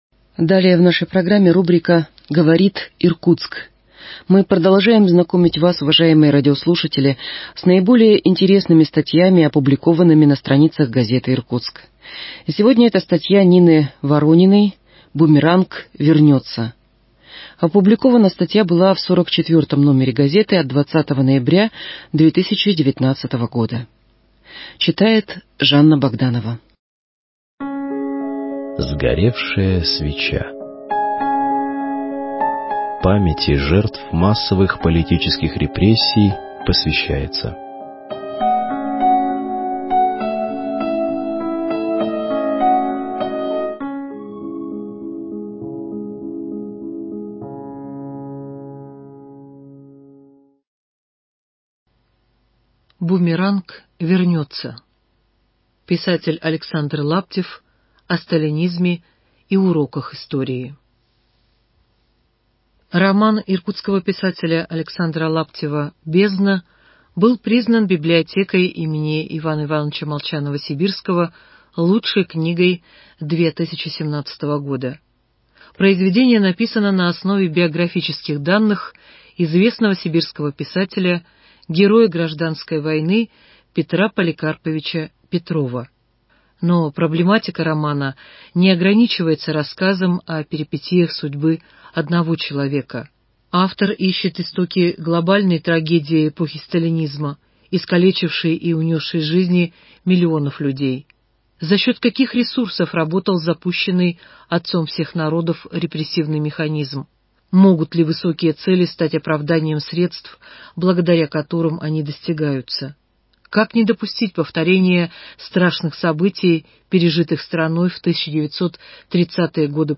Рубрика «Говорит Иркутск» - в эфире подкастов газеты "Иркутск" читаем лучшие статьи журналистов газеты «Иркутск».